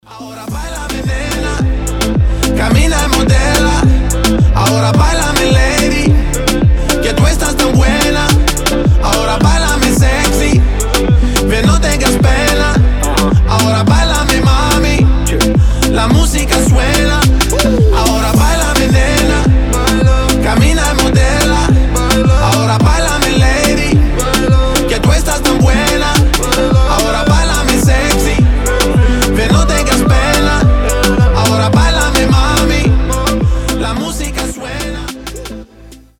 • Качество: 320, Stereo
мужской голос
заводные